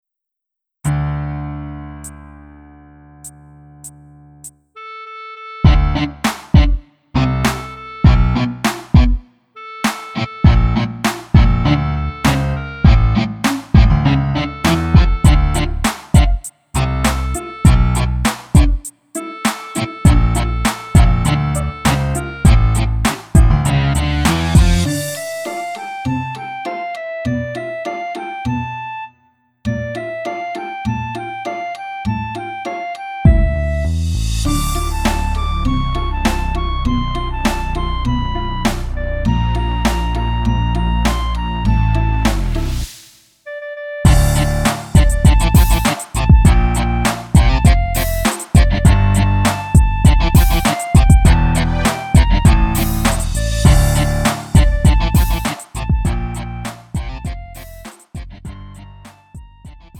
축가, 웨딩, 결혼식 MR. 원하는 MR 즉시 다운로드 가능.